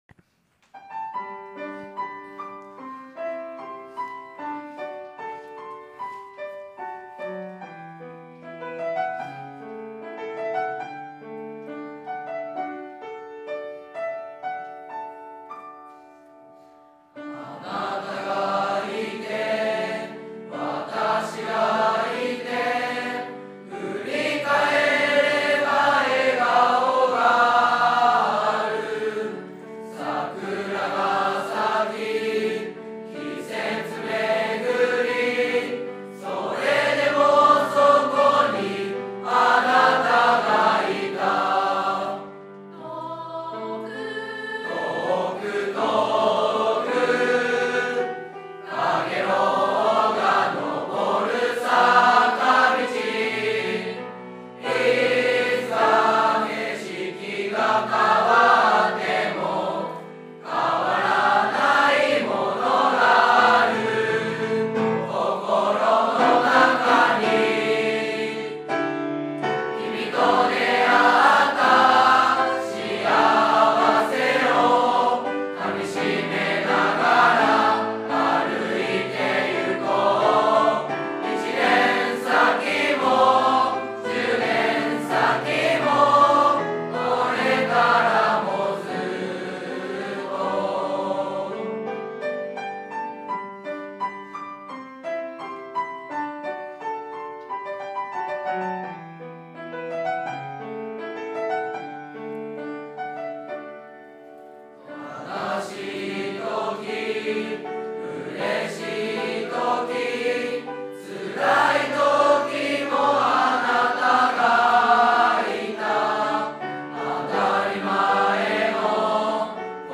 学習発表会での合奏や合唱を、音声だけになりますが掲載しました。